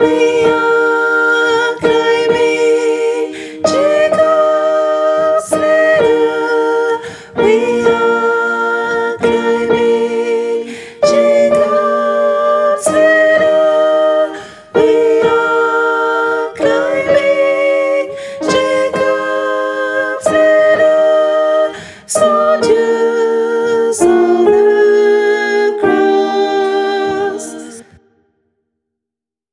Tenor et autres voix en arrière-plan